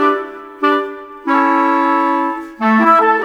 Rock-Pop 06 Winds 04.wav